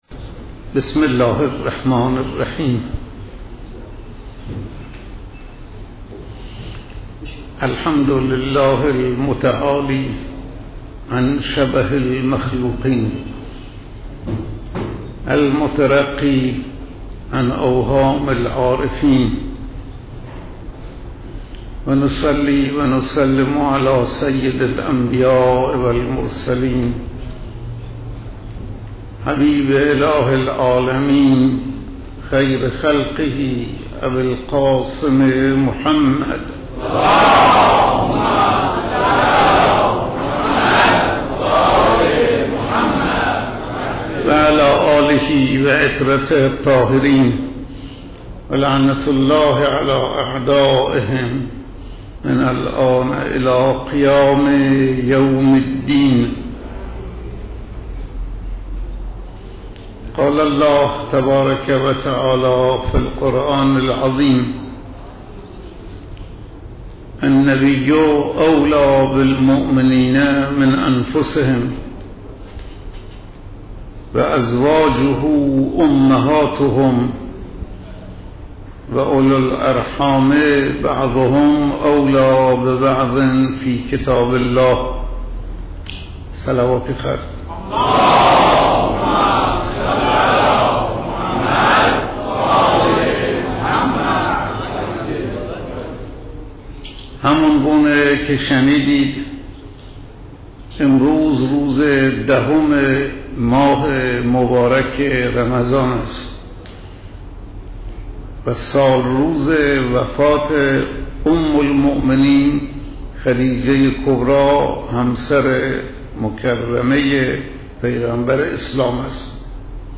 سخنرانی روز ۱۰ ماه رمضان - صوتی :: پایگاه خبری مسجد حضرت آیت الله شفیعی اهواز
‌ سخنرانی حضرت آیت الله شفیعی روز 10 ماه رمضان (شنبه ششم تیرماه)